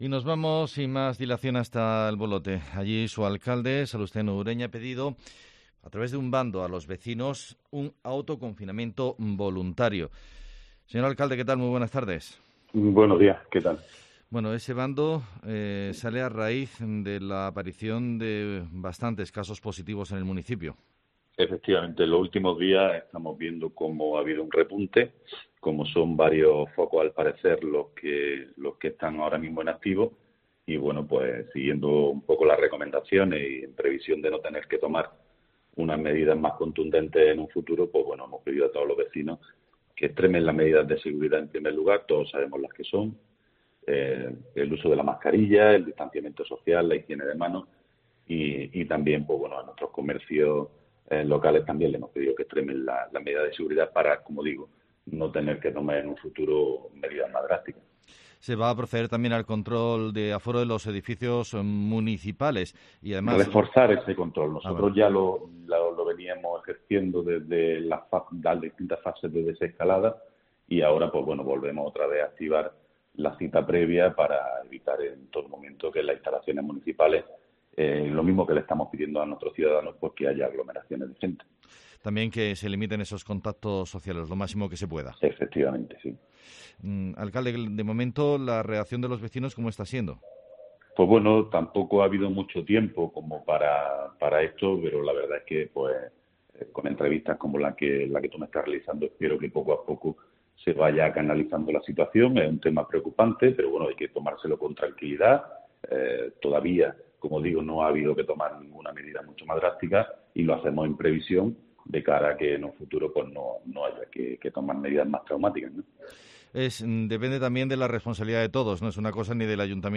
El alcalde nos habla de las medidas que han adoptado